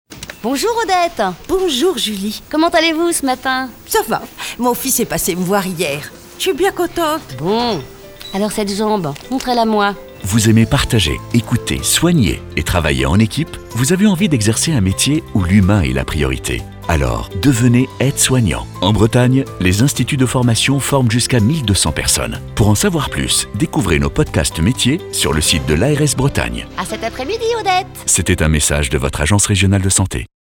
Témoignages aide-soignants - 26 novembre 2018